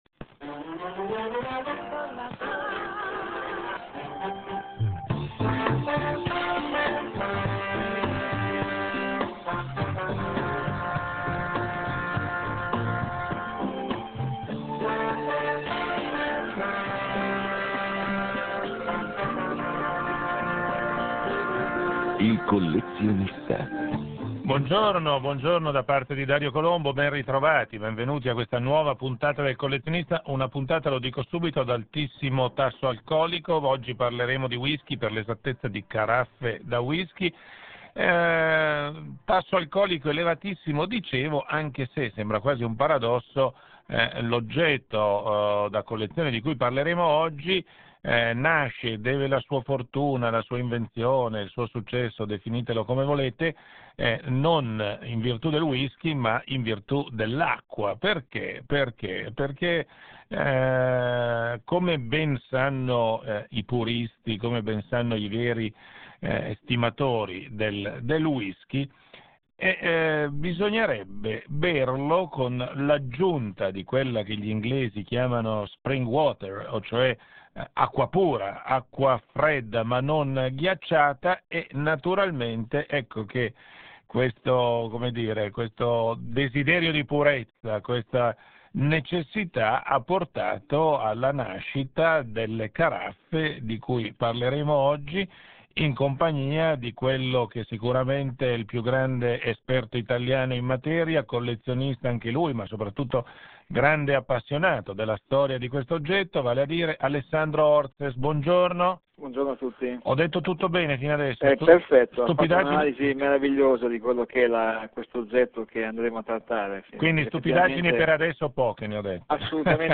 On 17 April 2004 I had the huge satisfaction to be interviewed as an expert of whisky-jugs, by , broadcasting of the Italian financial newspaper "Il Sole 24 ORE", which was dedicating a weekly program to the world of collections.